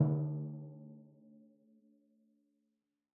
Timpani6D_hit_v3_rr1_main.mp3